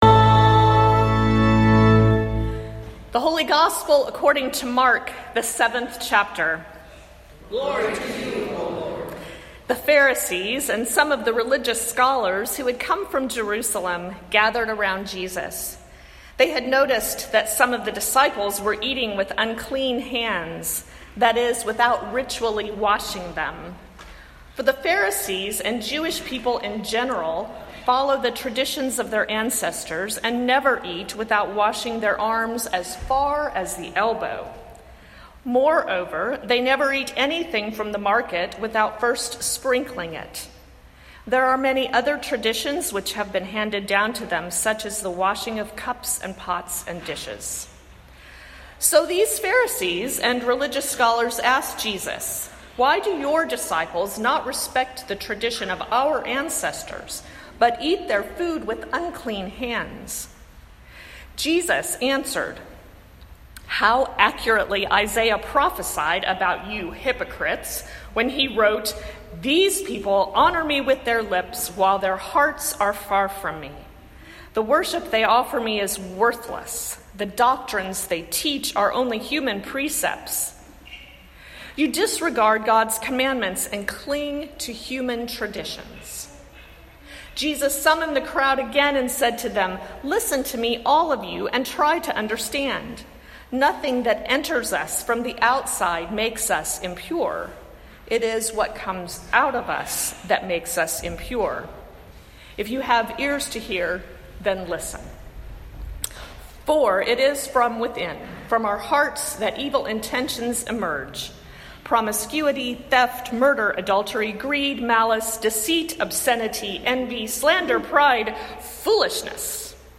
Luther Memorial Church Seattle, WA